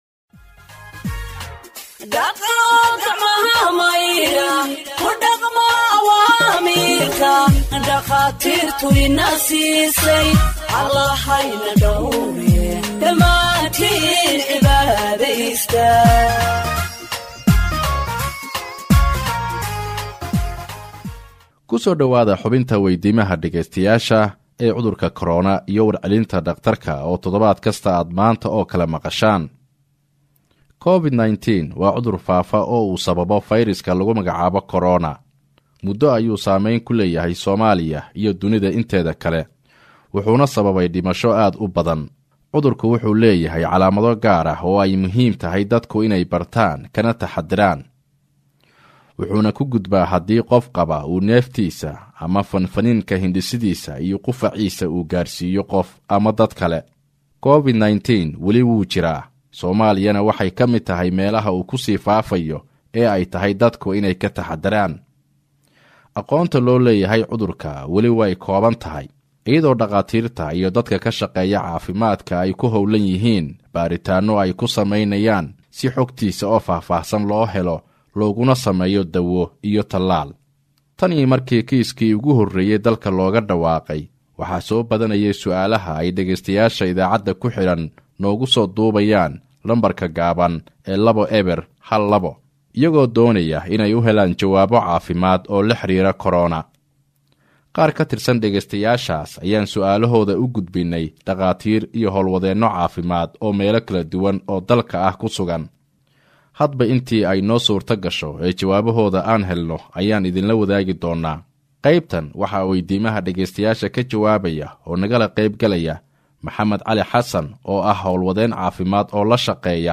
Health expert answers listeners’ questions on COVID 19 (43)